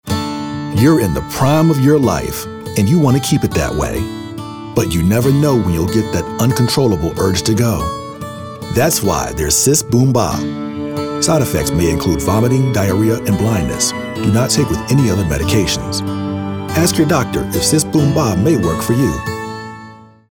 Smooth, warm, and informative - perfect for authentic sounding copy.
announcer, authoritative, caring, concerned, conversational, friendly, genuine, informative, Medical, sincere